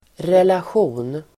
Uttal: [relasj'o:n]